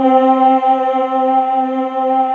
Index of /90_sSampleCDs/USB Soundscan vol.28 - Choir Acoustic & Synth [AKAI] 1CD/Partition D/27-VOIX SETS